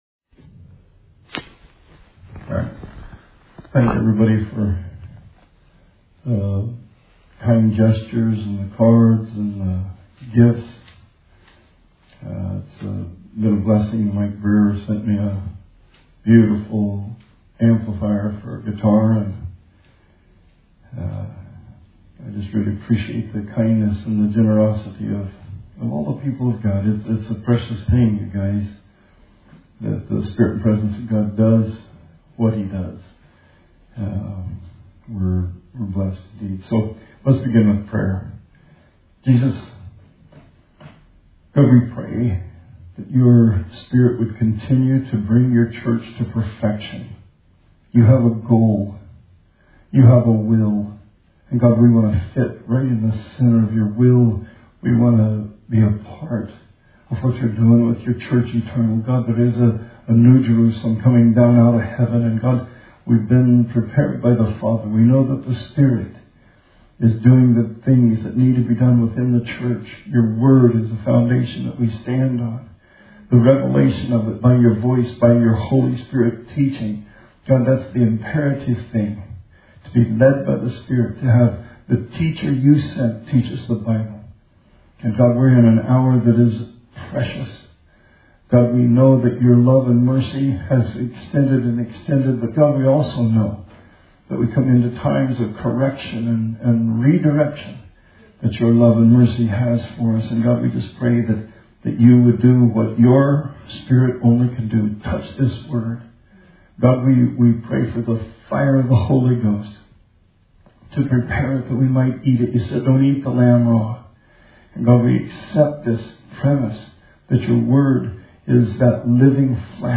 Bible Study 4/29/20